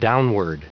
Prononciation du mot downward en anglais (fichier audio)
Prononciation du mot : downward